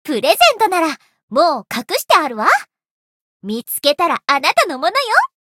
灵魂潮汐-叶月雪-春节（摸头语音）.ogg